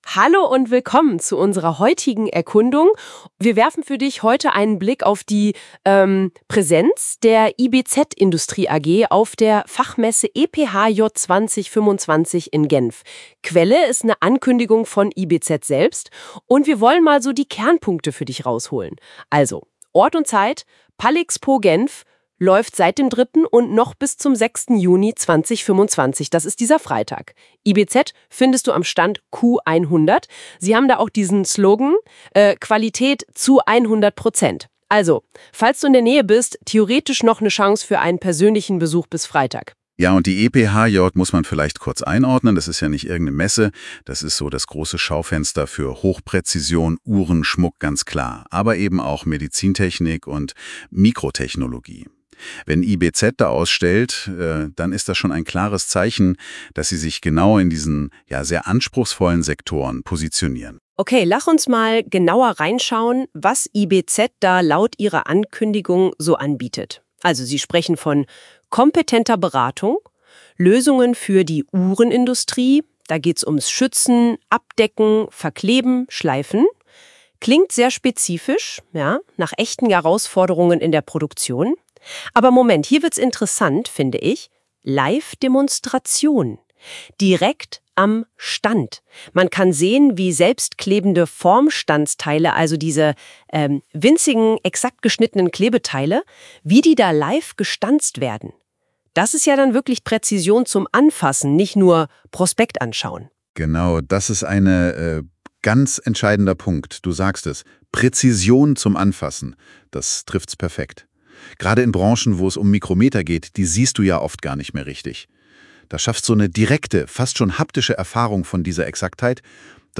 PS: Besuchen Sie uns noch bis Ende der Woche auf der EPHJ – der führenden Fachmesse für Hochpräzision. Im Podcast (erstellt mit KI-Unterstützung) erfahren Sie, warum sich ein Besuch lohnt!